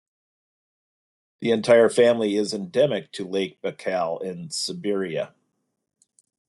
Pronounced as (IPA) /baɪ.ˈkæl/